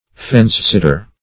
Meaning of fence-sitter. fence-sitter synonyms, pronunciation, spelling and more from Free Dictionary.